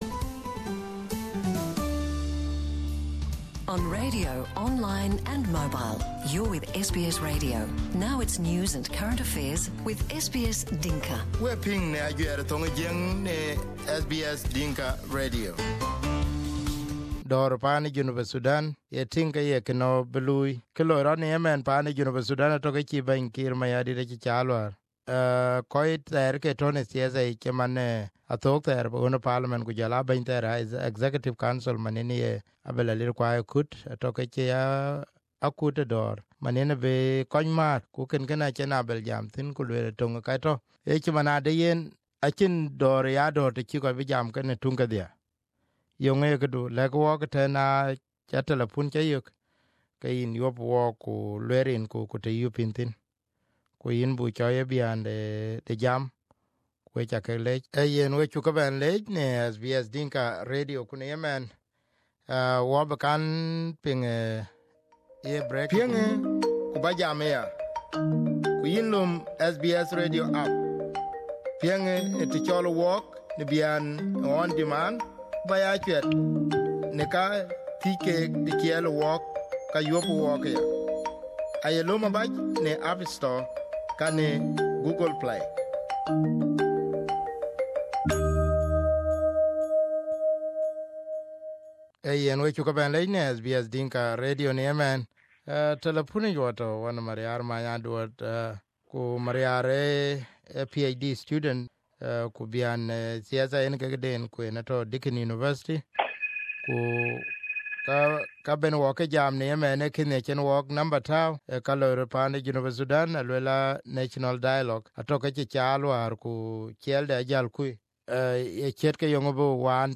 Here is the talkback.